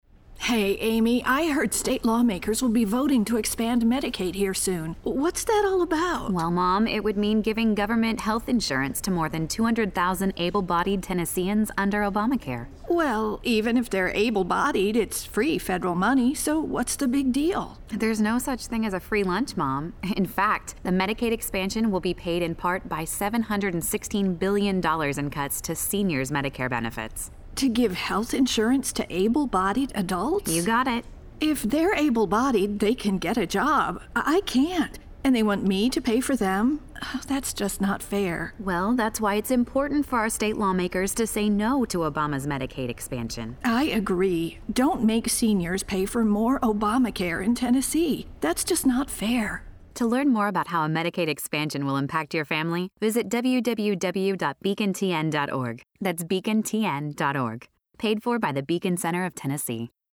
The 60-second ad, titled “That’s Just Not Fair,” features a discussion between a senior citizen and her daughter about the proposed Medicaid expansion. It is running on radio stations in the Knoxville media market.